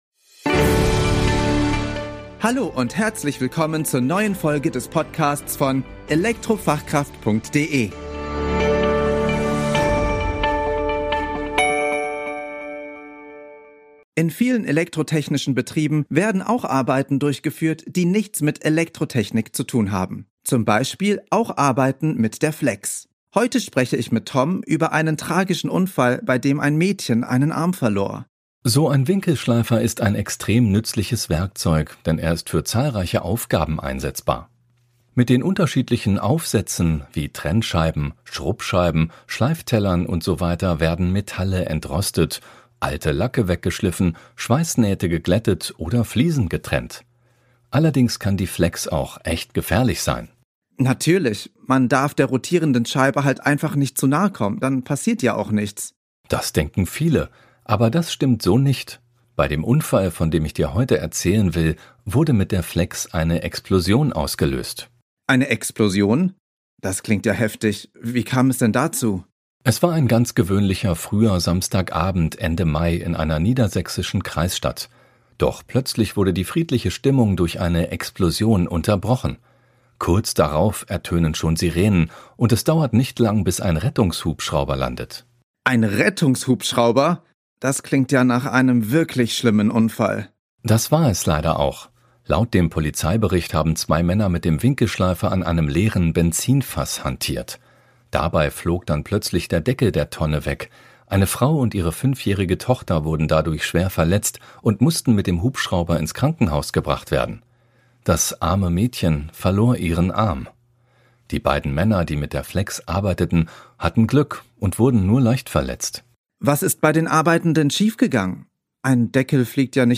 - Künstlich erzeugter Audioinhalt